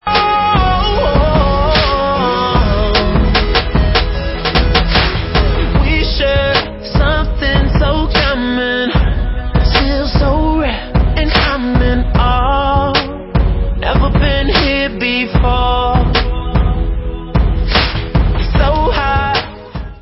sledovat novinky v oddělení Pop/Rhytm & Blues